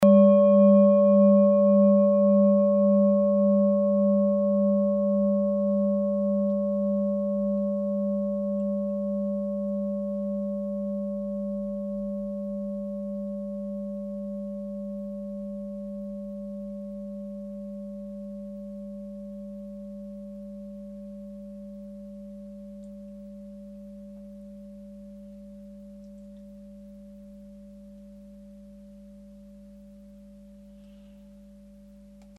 (Ermittelt mir dem Filzklöppel)
Wie aus dem Tonspektrum hervorgeht, handelt es sich hier um eine Planetentonschale Tageston.
Die Klangschale hat bei 194.09 Hz einen Teilton mit einer
Die Klangschale hat bei 532.22 Hz einen Teilton mit einer
Die Klangschale hat bei 988.03 Hz einen Teilton mit einer
klangschale-orissa-21.mp3